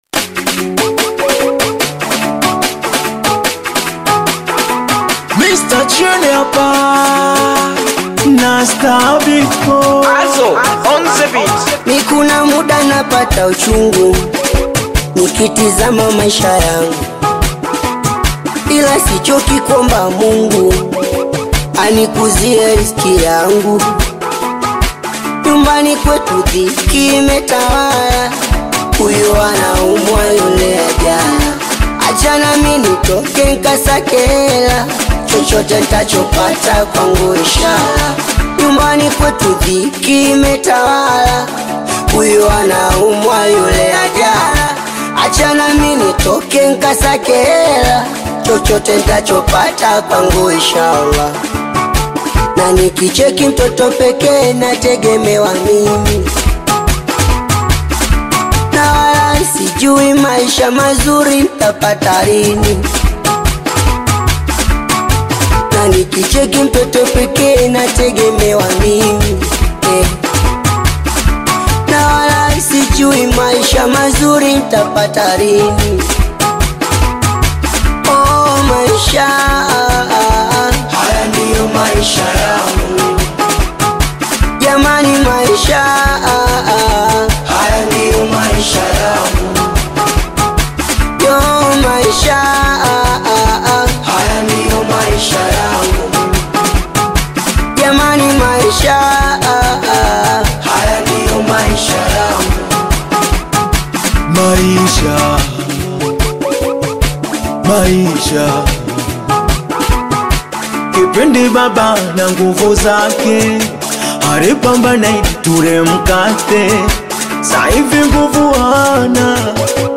a high-speed anthem